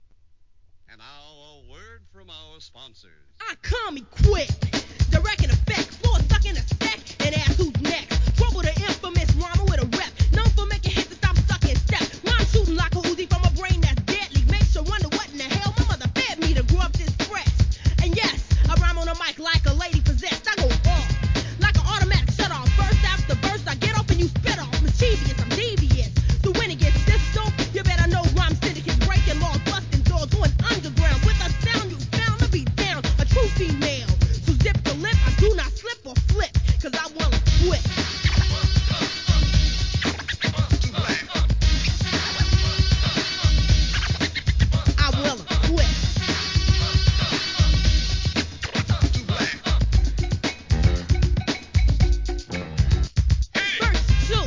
HIP HOP/R&B
フィメールRAP!!